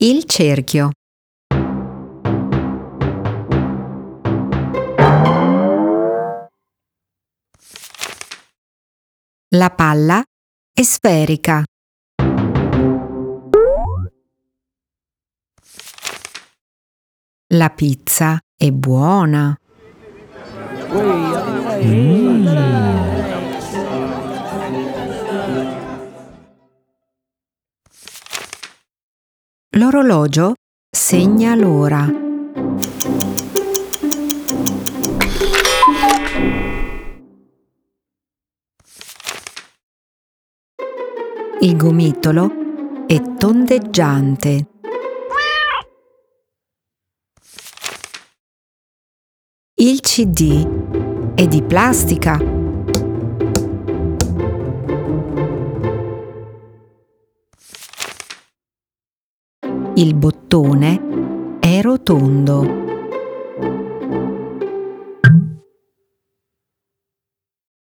Booktrailer in stop-motion